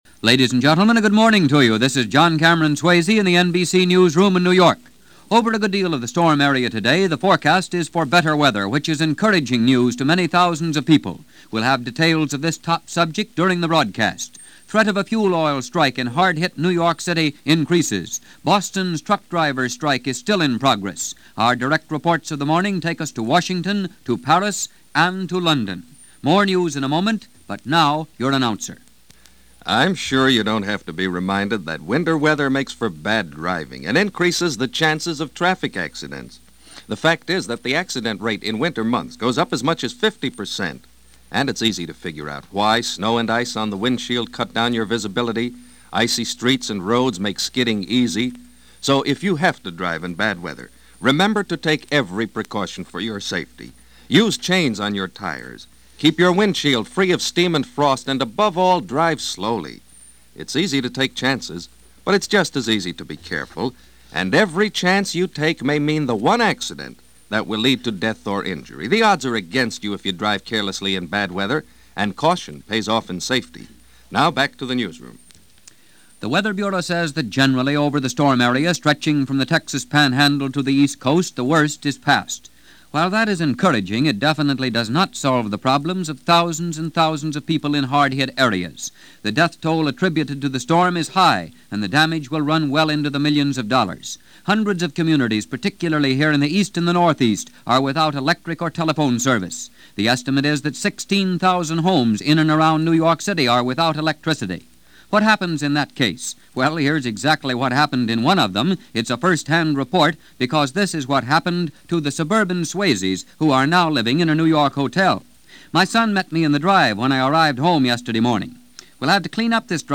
January 3, 1948 - Weather Is Here, Wish You Were Beautiful - The Frigid East - News for this day in 1948 from John Cameron Swayze.